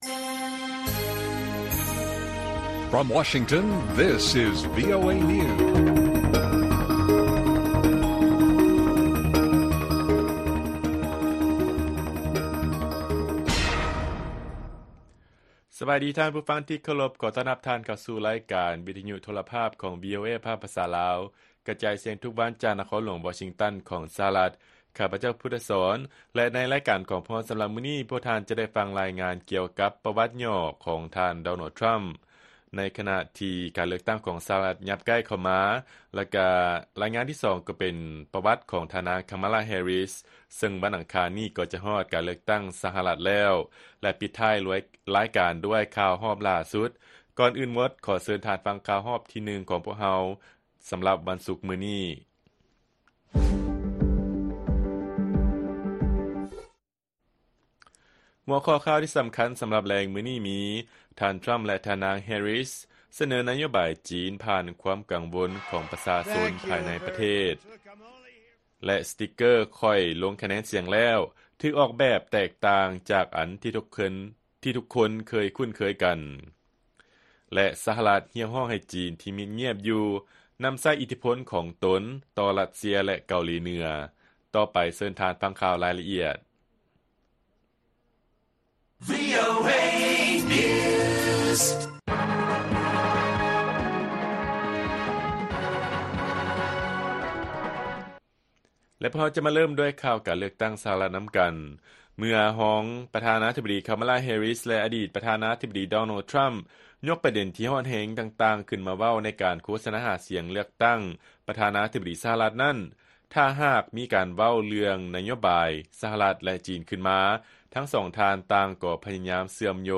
ລາຍການກະຈາຍສຽງຂອງວີໂອເອລາວ: ທ່ານ ທຣຳ ແລະ ທ່ານນາງ ແຮຣິສ ສະເໜີນະໂຍບາຍ ຈີນ ຜ່ານຄວາມກັງວົນຂອງປະຊາຊົນພາຍໃນປະເທດ